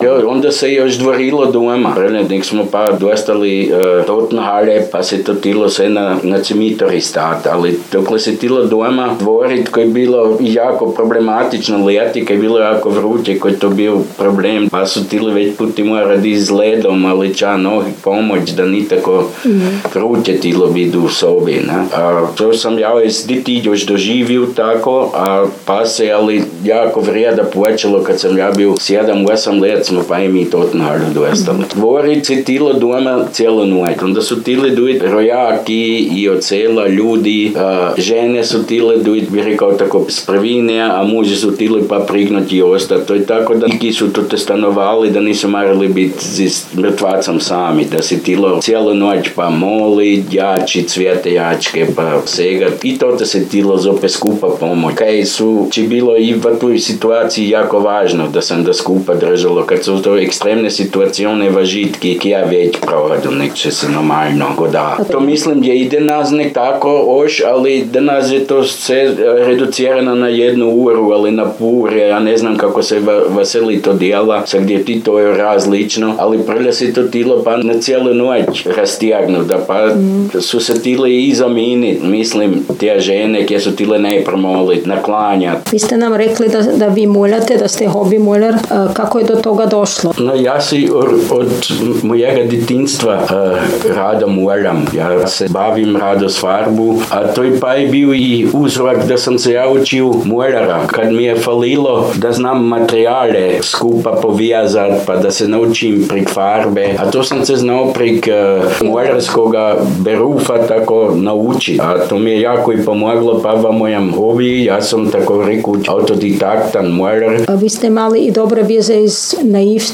Klimpuh – Govor
23_Klimpuh_govor.mp3